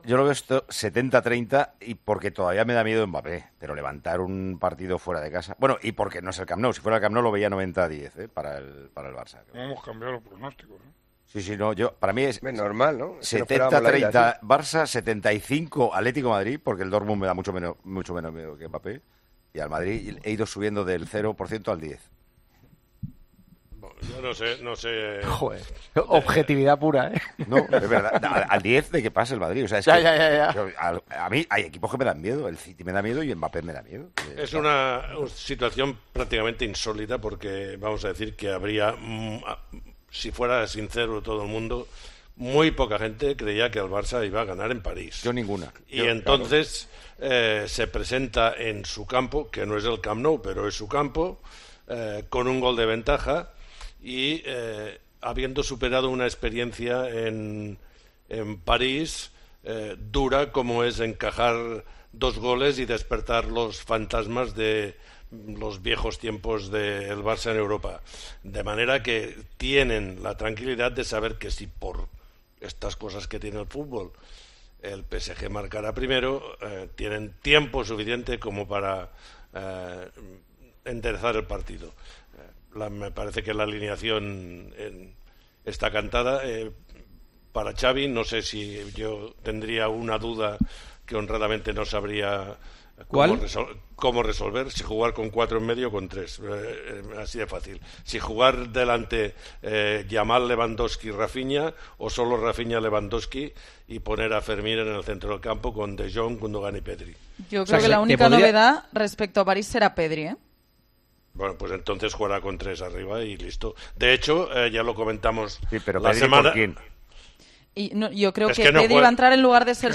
El exfutbolista y comentarista de Tiempo de Juego se mostró preocupado por el plantemiento de Xavi Hernández para la vuelta de los cuartos de final de la Liga de Campeones.